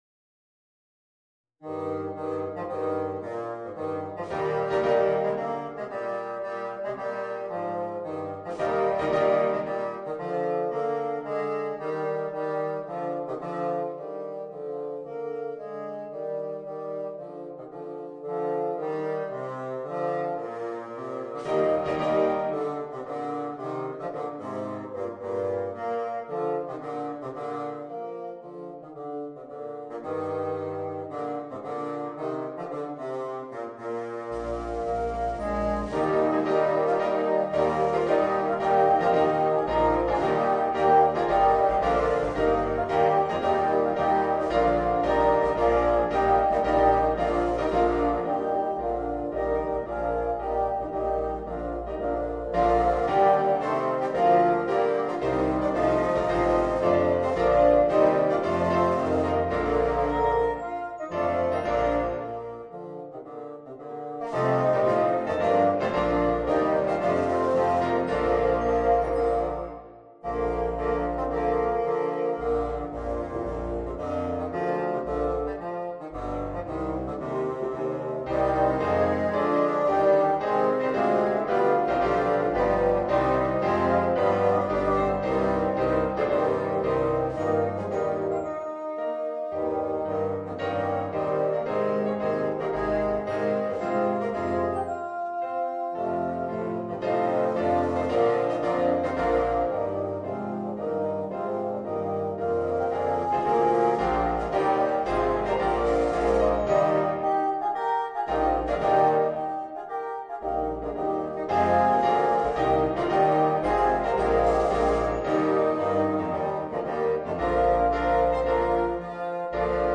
Voicing: 8 Bassoons